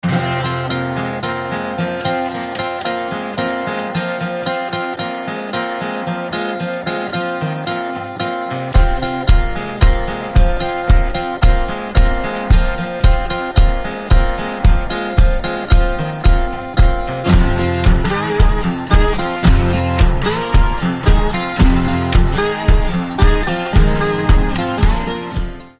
• Качество: 256, Stereo
гитара
без слов
вестерн
ковбойские
country